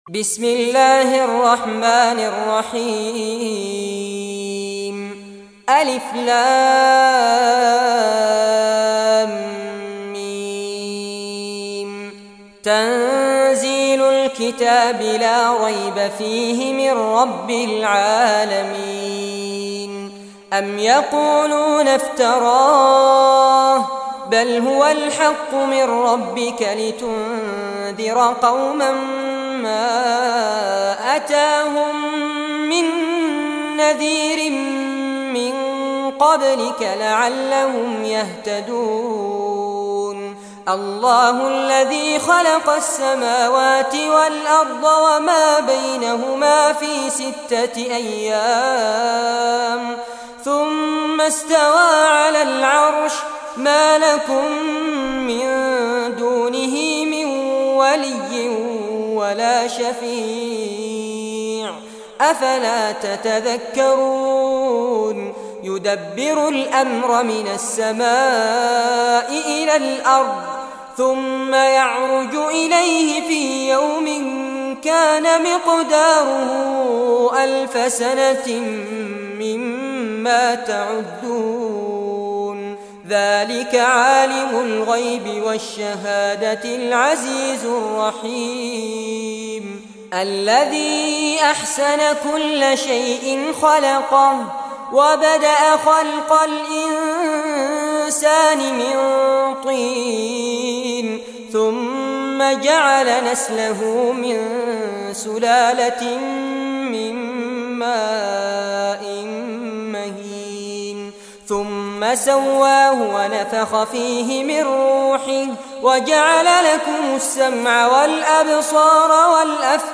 Surah As-Sajdah Recitation by Fares Abbad
Surah As-Sajdah, listen or play online mp3 tilawat / recitation in Arabic in the beautiful voice of Sheikh Fares Abbad.
32-surah-sajdah.mp3